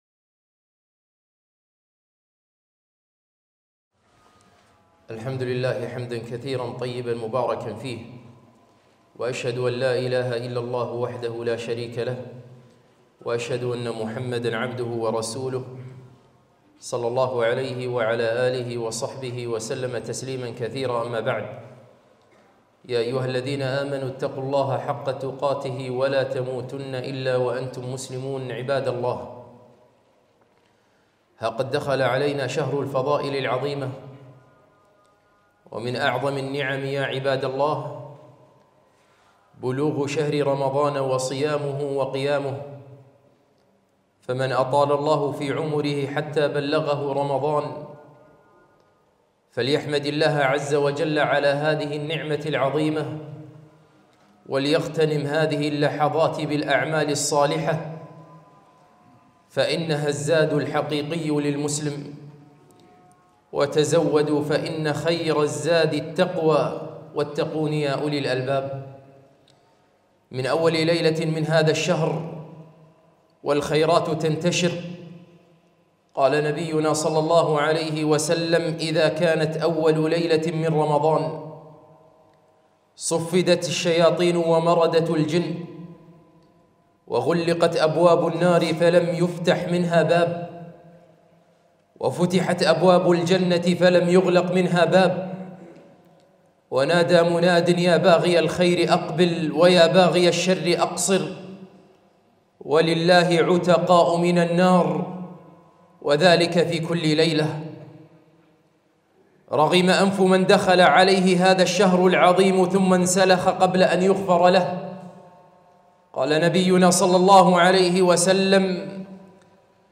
خطبة - فضائل رمضان